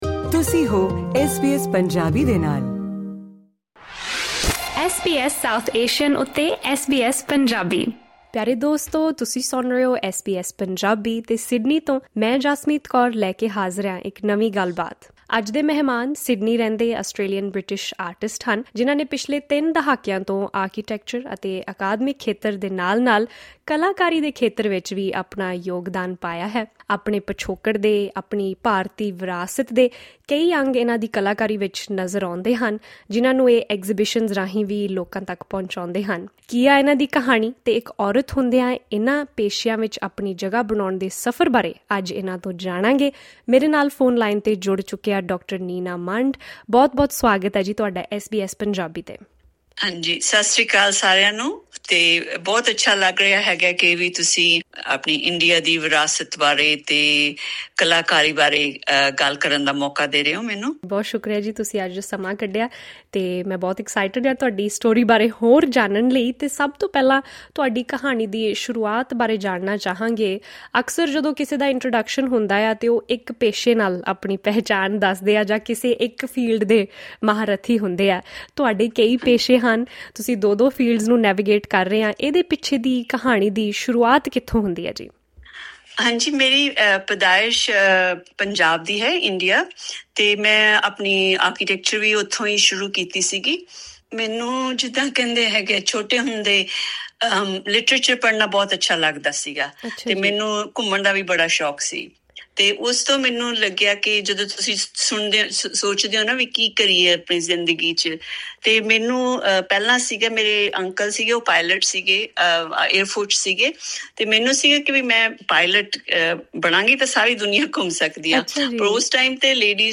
Now turning to art, she draws inspiration from the Indian literary concept of 'Dhvani' to create abstract works. In this conversation, she reflects on her journey — from navigating a male-dominated profession to inspiring younger generations to pursue their passions and carve their own paths.